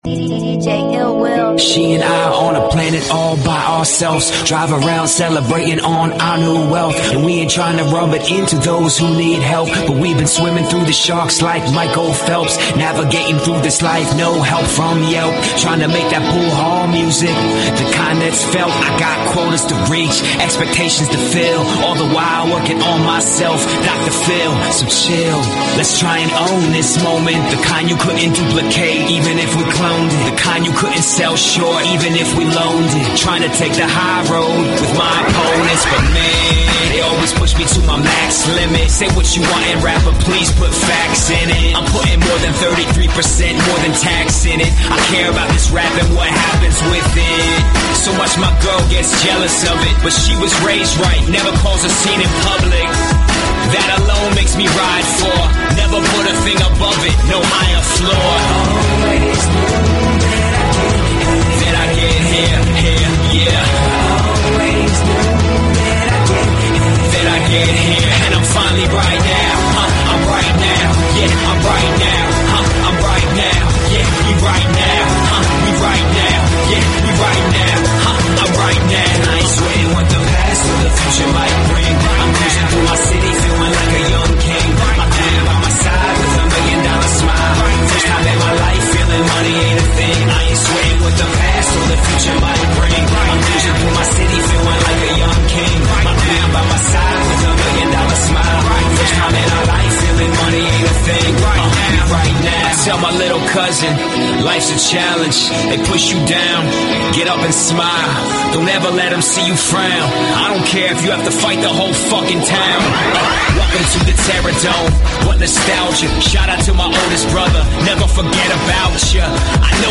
Talk Show Episode, Audio Podcast, Todays_Entrepreneurs and Courtesy of BBS Radio on , show guests , about , categorized as
During each broadcast, there will be in-depth guest interviews discussing the problems and advantages business owners face. Topics will include sales and marketing, branding, interviewing, and much more.
In addition to daily guests, TE spins the hottest indie musical artists and laughs along to the funniest comedians every day.